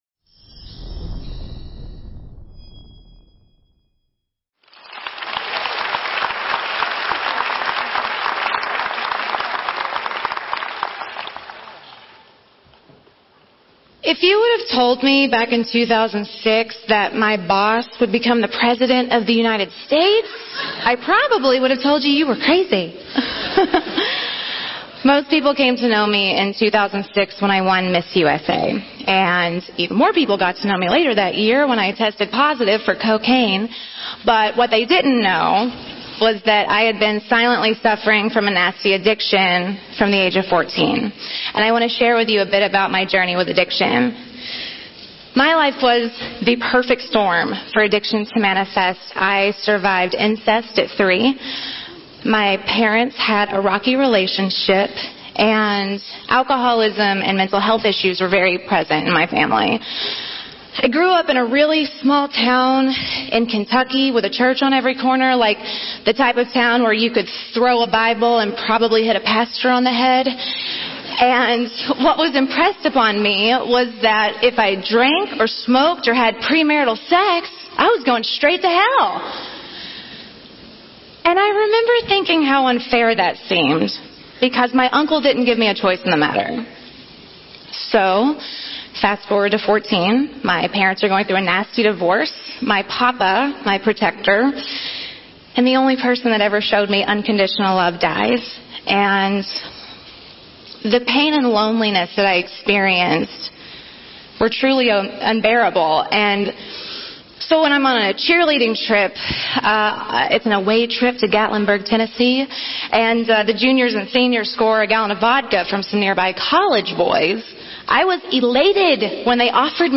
Here is the full transcript of Miss USA 2006 Tara Conner’s TEDx Talk presentation: Recover Out Loud at TEDxUniversityofNevada conference.